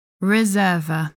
因此，我们录制了一些关键葡萄酒术语并创建了这个读音指南。
我们的宗旨是以较为被世界广泛人接受的读音来作参考。